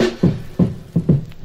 Chopped Fill 6.wav